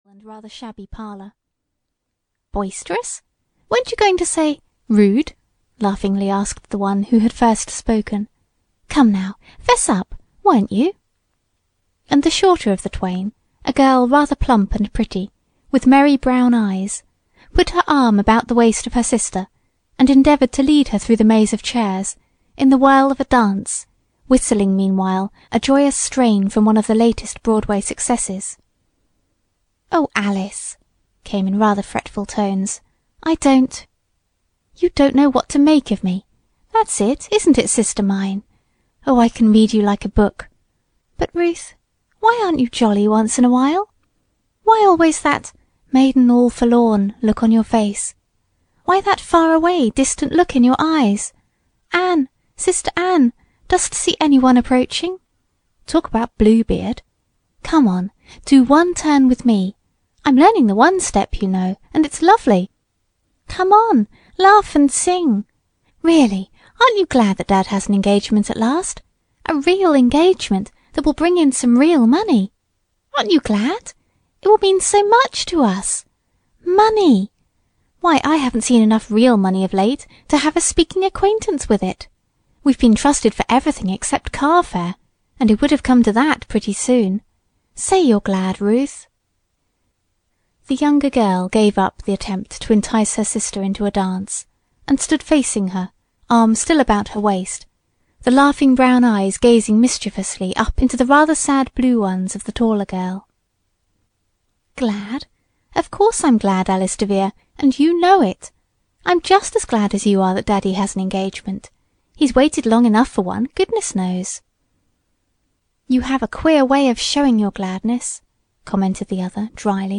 Audio knihaThe Moving Picture Girls (EN)
Ukázka z knihy